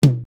LM-1_Tom_2_TL.wav